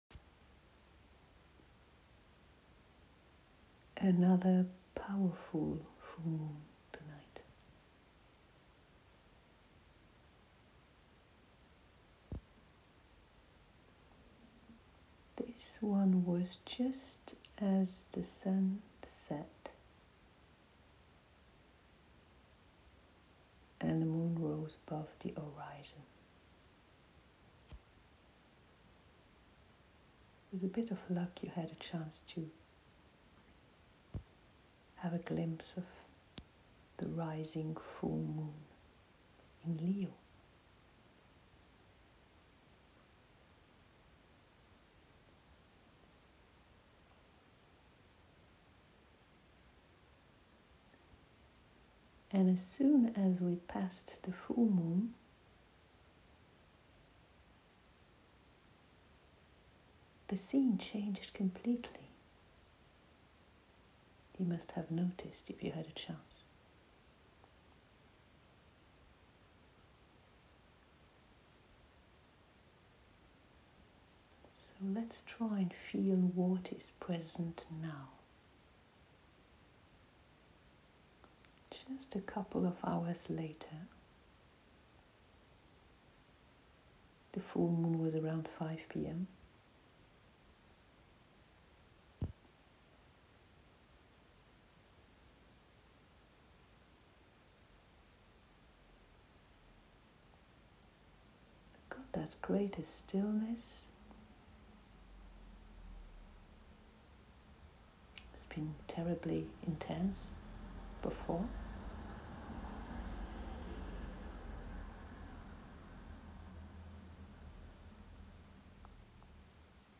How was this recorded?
a meditation circle in the evening of the Lion full Moon on 16 February 2022 (it was at 3:56pm GMT) overstretched or in expansion, depending on the view point centred in yourself you can experience the opening consciously